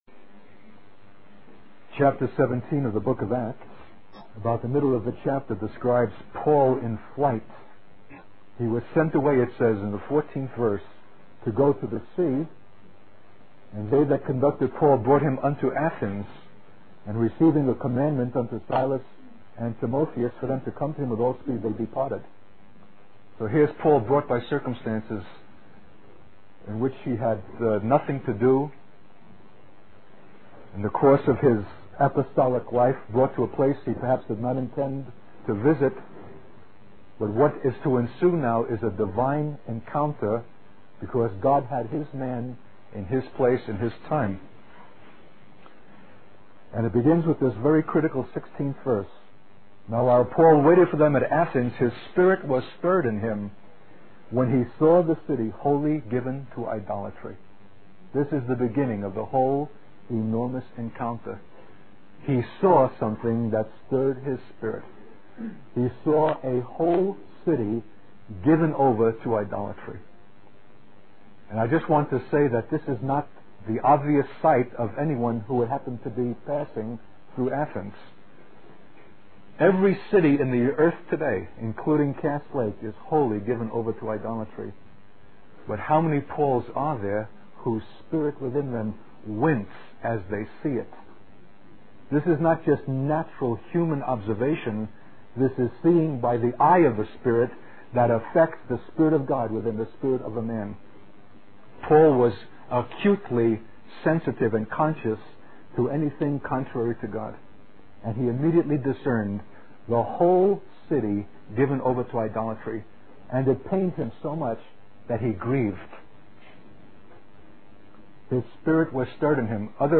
In this sermon, the speaker discusses the challenges of teaching and preaching the word of God. He shares his personal experience of teaching a compulsory class on American history to students who were disinterested and involved in various negative behaviors.